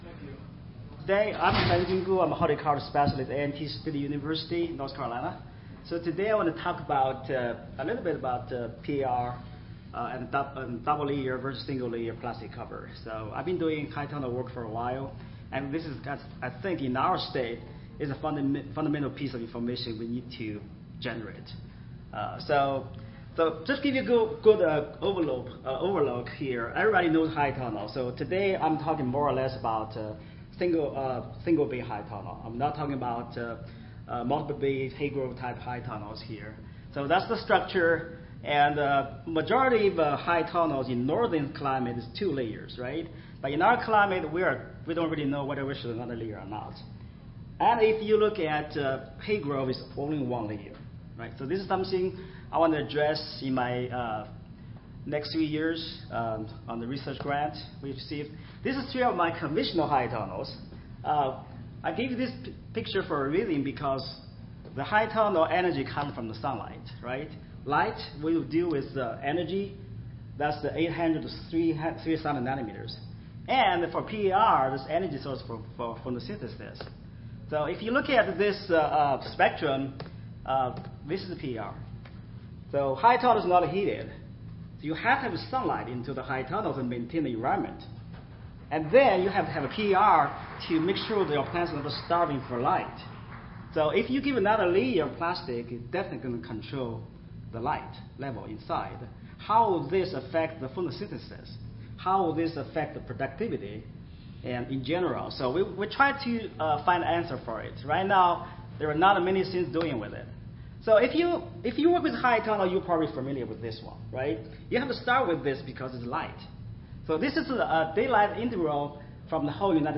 2019 ASHS Annual Conference
Audio File Recorded Presentation